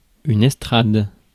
Ääntäminen
IPA: /ɛs.tʁad/